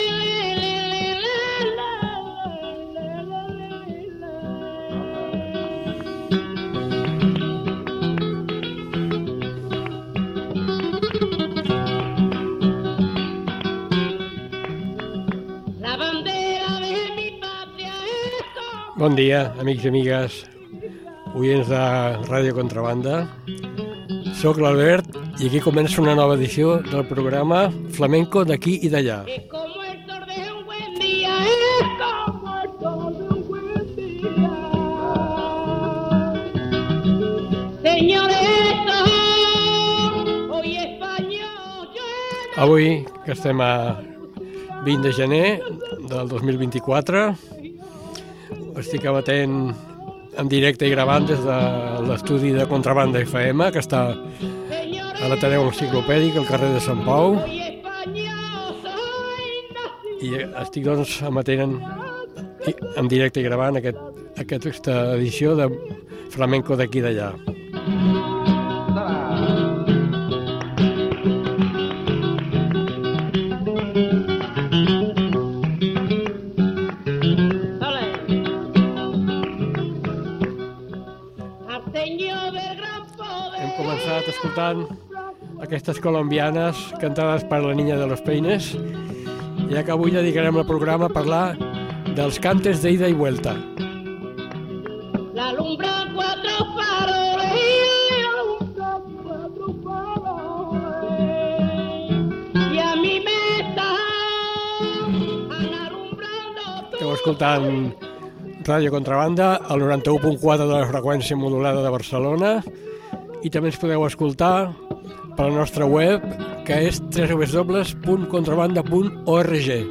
Cantes flamencos resultat dels intercanvis culturals amb els països de l’antic imperi espanyol.